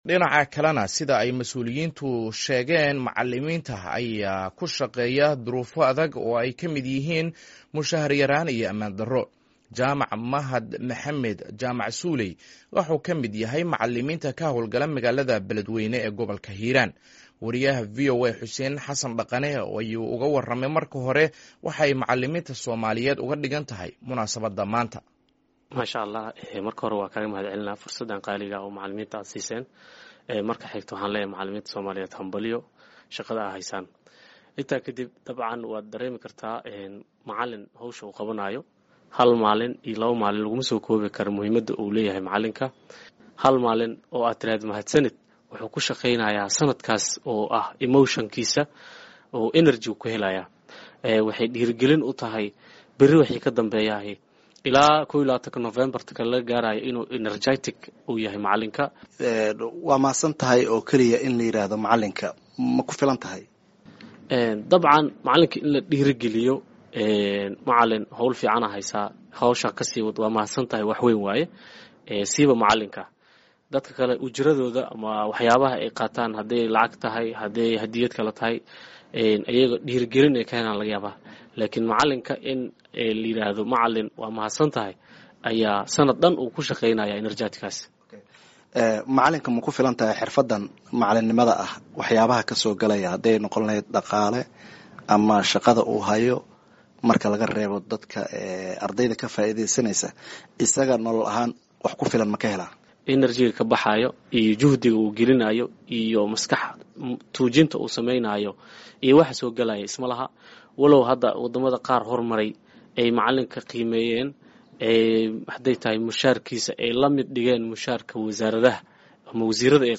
Wareysi: Duruufaha ay ku shaqeeyaan macallimiinta Soomaaliyeed